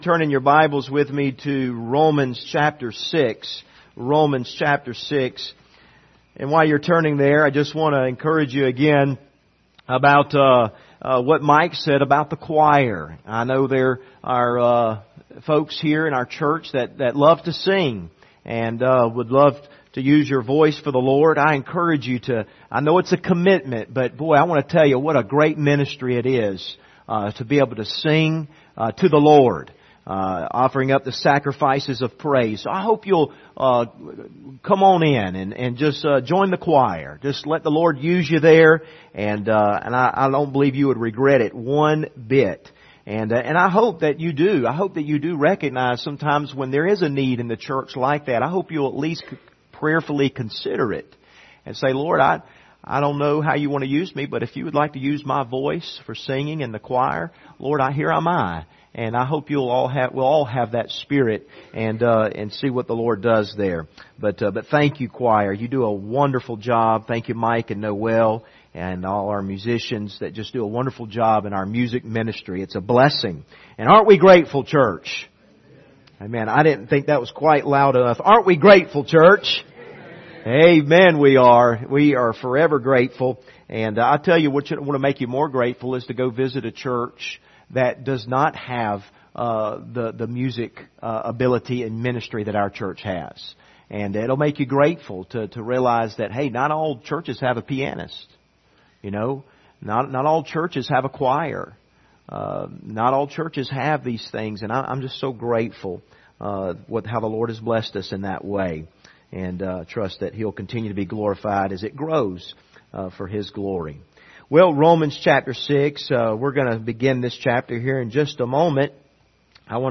Passage: Romans 6:1-4 Service Type: Sunday Morning